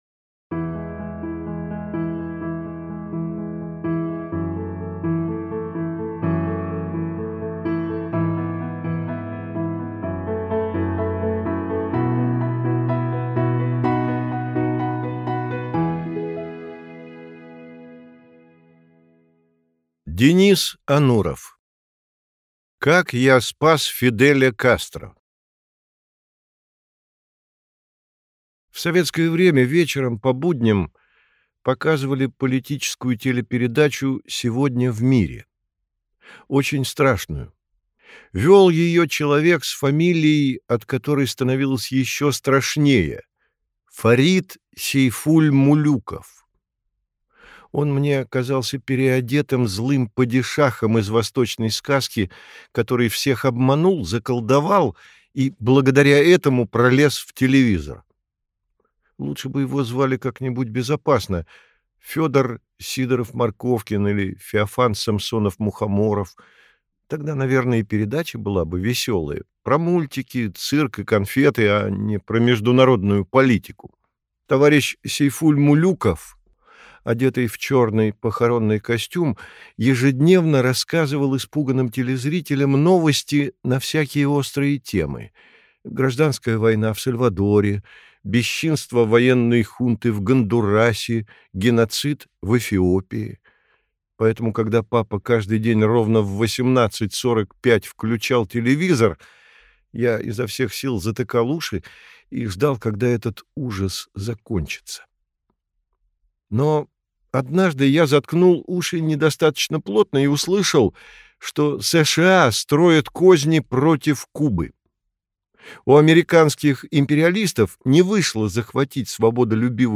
Аудиокнига Как я спас Фиделя Кастро | Библиотека аудиокниг